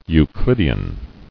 [Eu·clid·e·an]